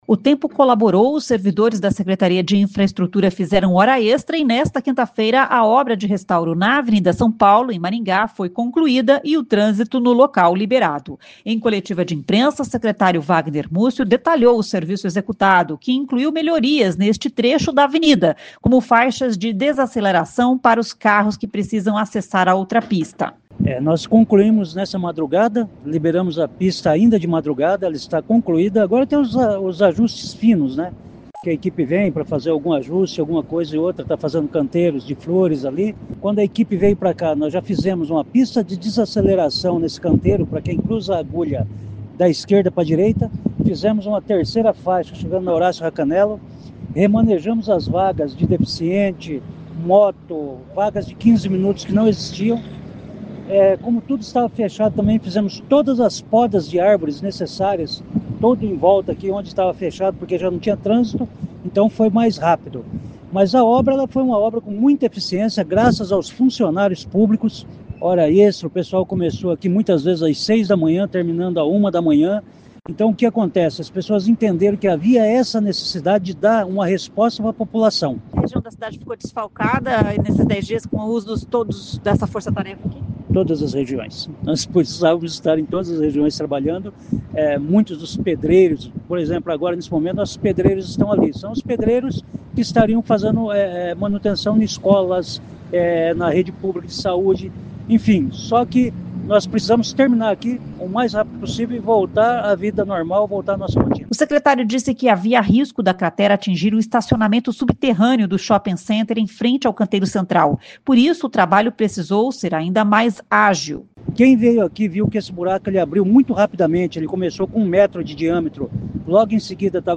Em coletiva de imprensa, o secretário Vagner Mussio, detalhou o serviço executado, que incluiu melhorias neste trecho da avenida, como faixas de desaceleração para os carros que precisam acessar a outra pista.